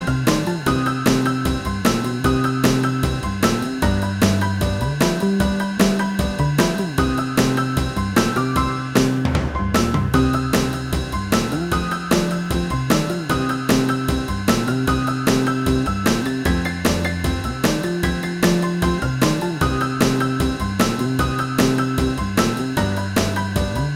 Minus Guitars Rock 'n' Roll 2:57 Buy £1.50